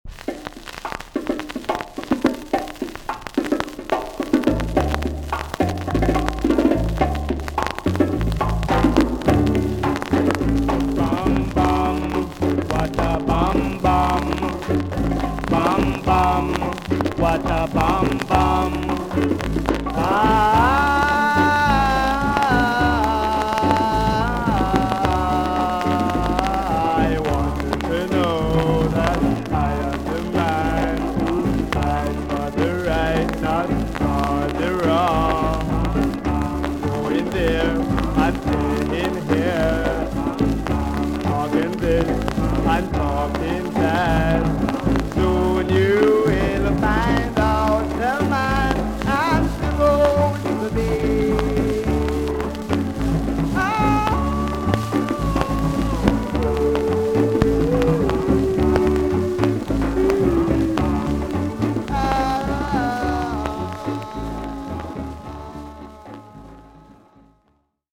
TOP >SKA & ROCKSTEADY
VG ok 全体的にチリノイズが入ります。